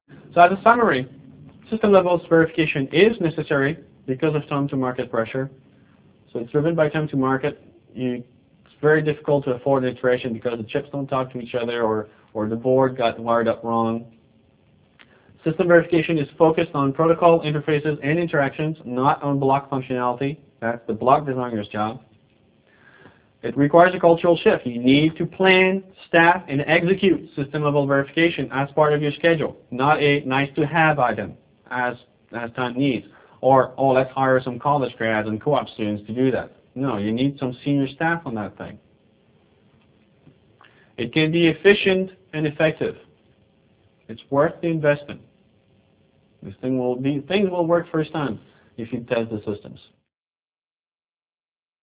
Online Seminar: A Strategic Process for System Level Verification, Slide 42 of 44